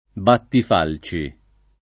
vai all'elenco alfabetico delle voci ingrandisci il carattere 100% rimpicciolisci il carattere stampa invia tramite posta elettronica codividi su Facebook battifalce [ battif # l © e ] o battifalci [ battif # l © i ] s. m. (agr.); inv.